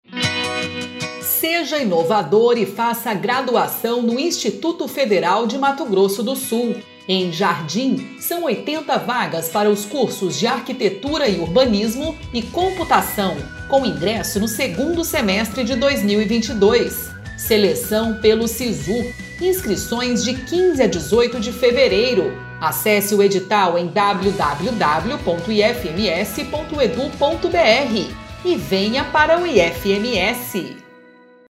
Áudio enviado às rádios para divulgação institucional do IFMS.